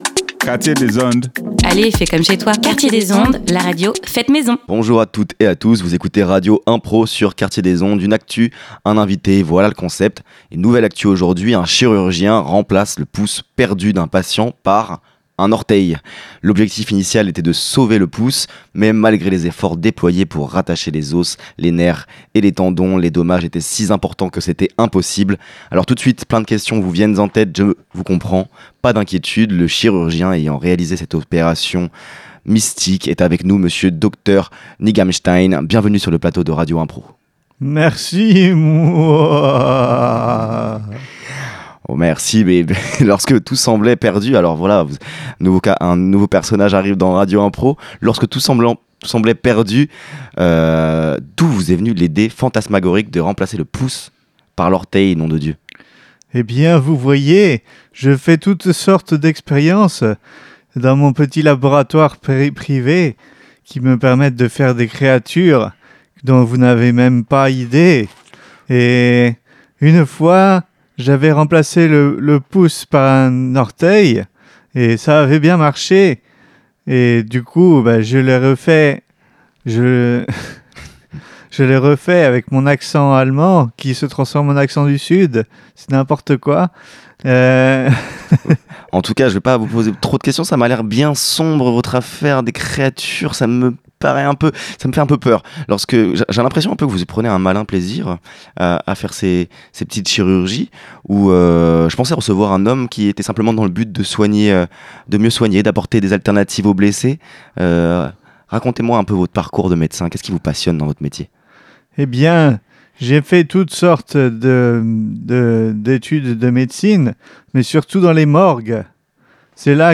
Tout cela crée des interviews tantôt humoristiques, tantôt poétiques, tantôt engagées et parfois un peu de tout ça !